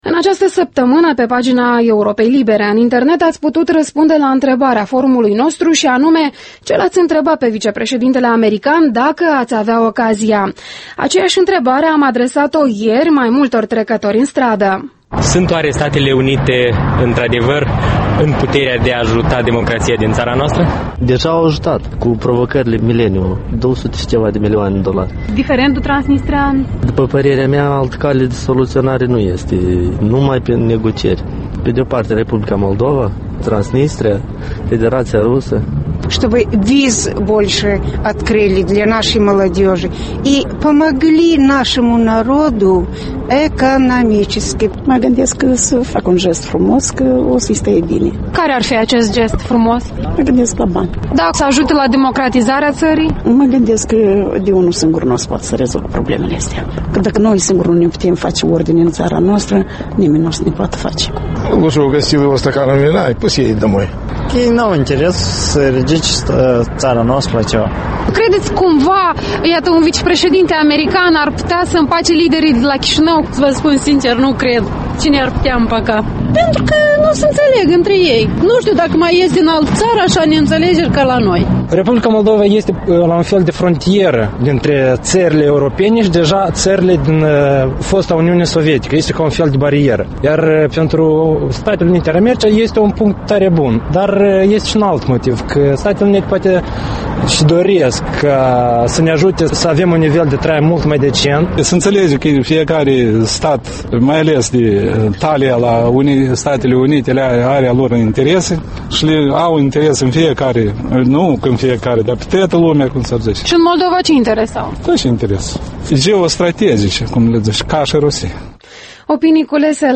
Opinii culese pe străzile Chișinăului.
Vox pop în ajunul vizitei vicepreședintelui Biden la Chișinău